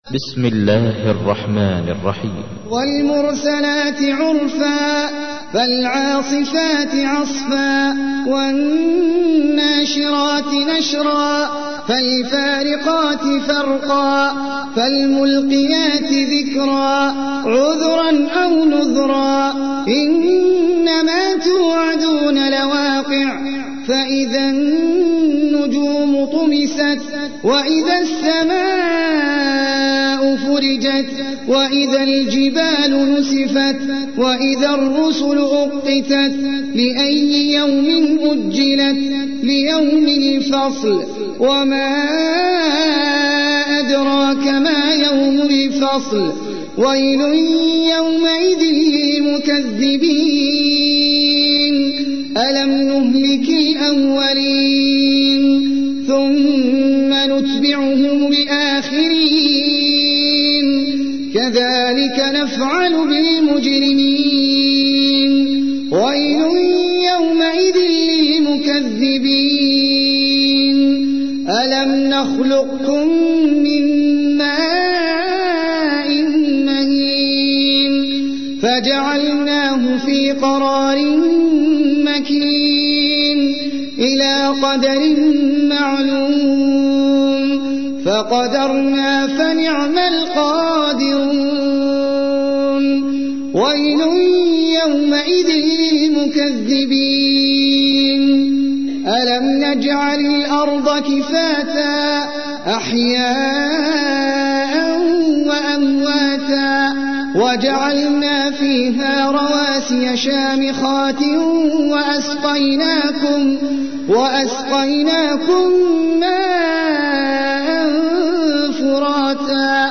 تحميل : 77. سورة المرسلات / القارئ احمد العجمي / القرآن الكريم / موقع يا حسين